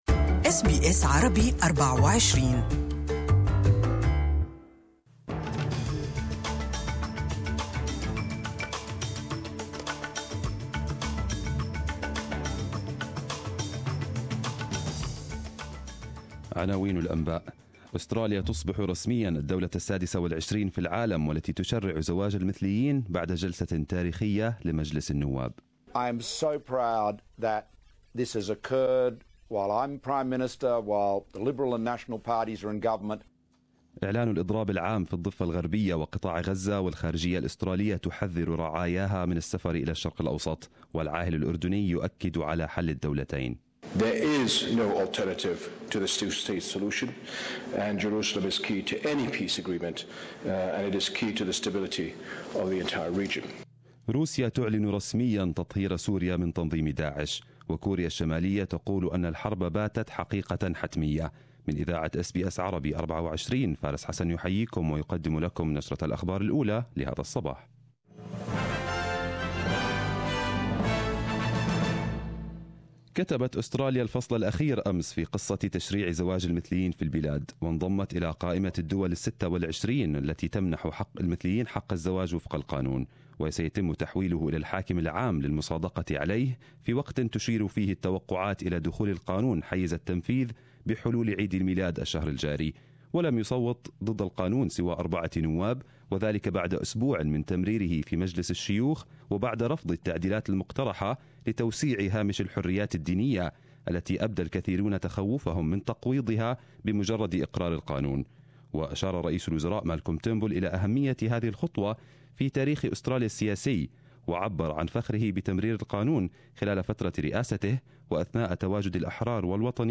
Arabic News Bulletin 08/12/2017